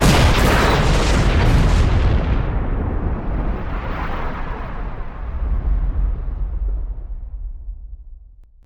OtherDestroyed7.wav